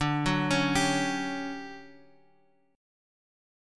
Listen to DM11 strummed